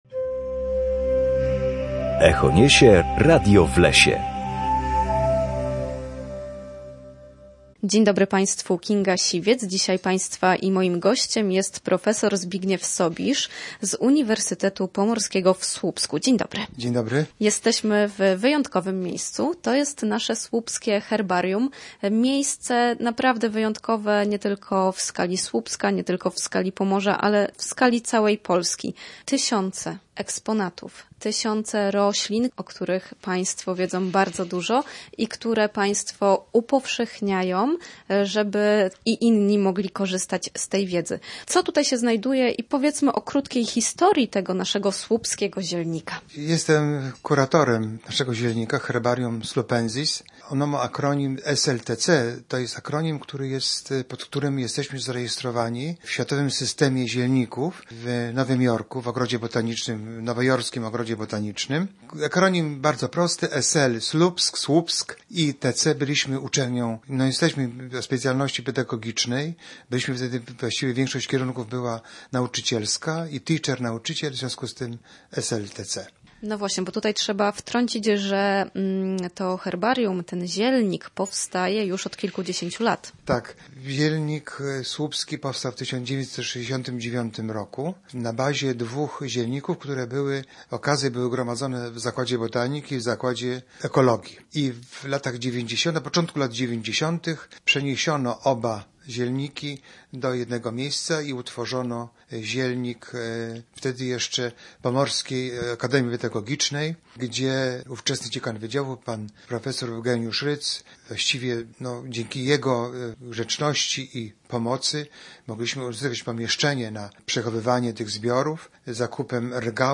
W każdą środę o godzinie 7:20 oraz o godzinie 14:10 na antenie Studia Słupsk rozmawiamy o naturze i sprawach z nią związanych.